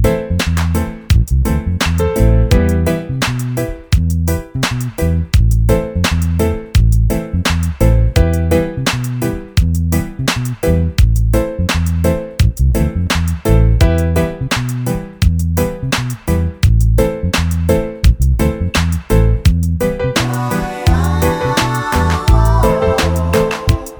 no Backing Vocals Reggae 4:13 Buy £1.50